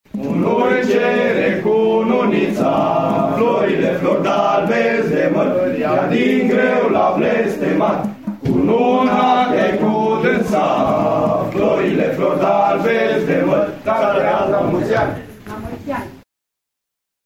In satul Viștea de Jos, situat la aproximativ 100 km de municipiul Brașov, tineri, copii, dar și bătrâni – organizati în cete – se strâng în fiecare săptămână fie la șezătoare, fie pentru a cânta colinde sau pentru a repeta tradiționalul repertoriu de cântece populare cu care vor fi colindate gazdele din sat: